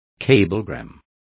Complete with pronunciation of the translation of cablegrams.